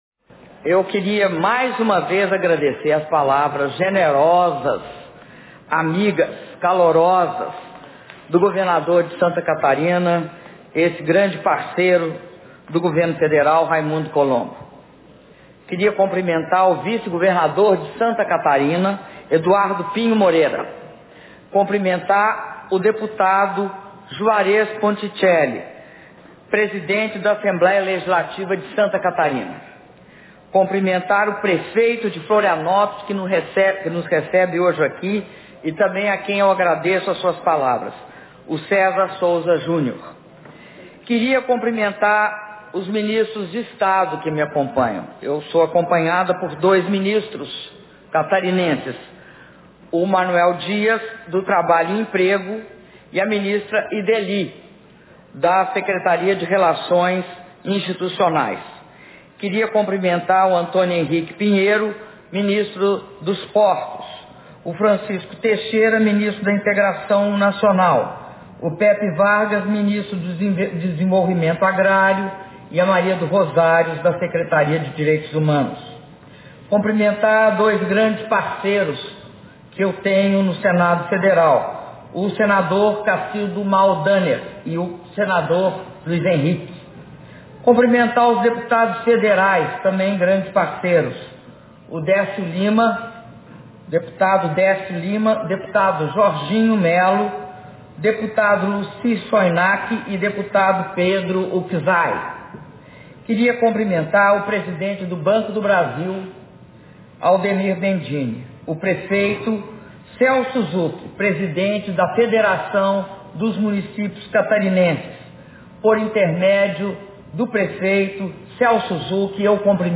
Áudio do discurso da Presidenta da República, Dilma Rousseff, na cerimônia de assinatura de contratos de financiamento entre o Banco do Brasil e o estado de Santa Catarina e de dragagem do Porto de Imbituba - Florianópolis/SC